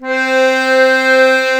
C4 ACCORDI-L.wav